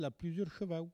Langue Maraîchin
locutions vernaculaires